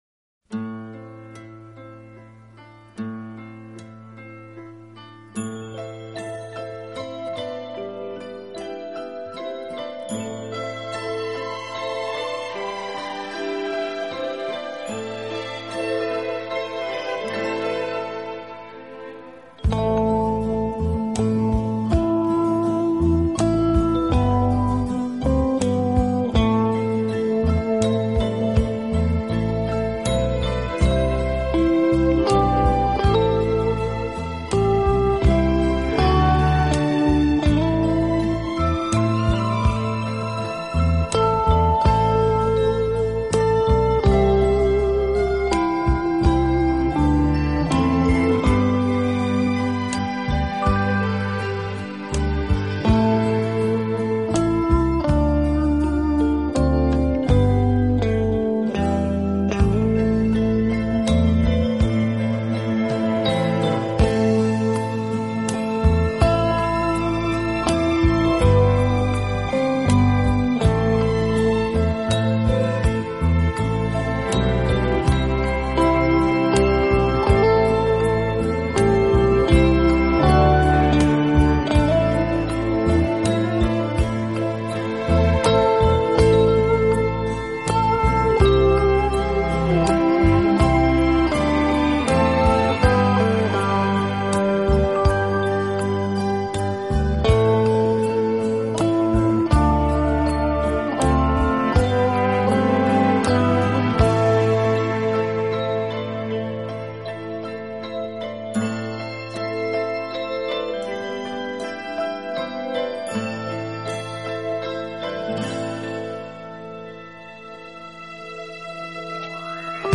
Genre: Easy Listening
温柔、宁静、娓娓动听。
是乐队演奏的主要乐器，配以轻盈的打击乐，使浪漫气息更加浓厚。